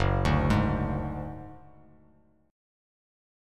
Gbsus2#5 chord